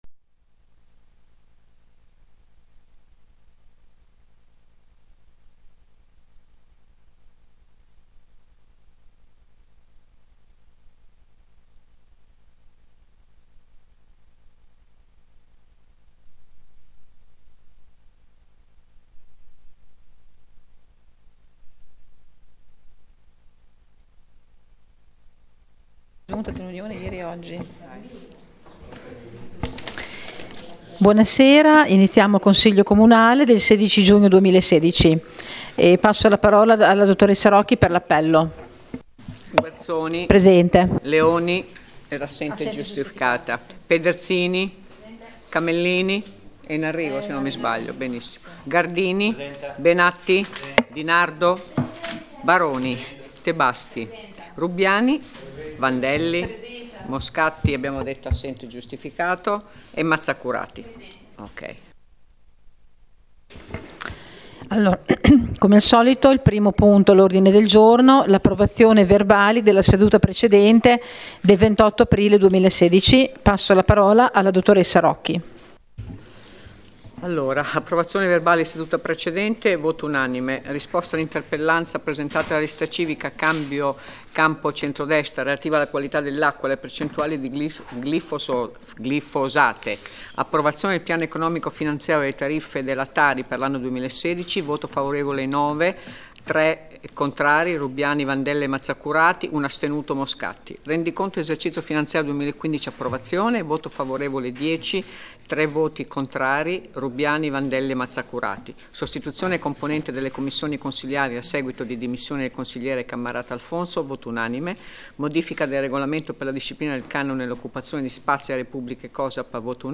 Accedendo a questa pagina è possibile ascoltare la registrazione della seduta del Consiglio comunale.